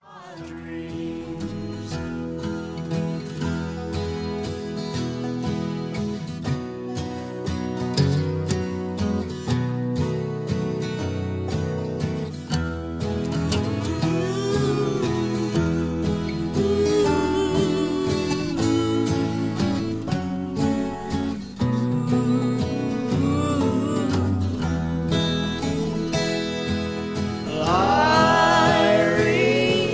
(Key-E)
Tags: backing tracks , irish songs , karaoke , sound tracks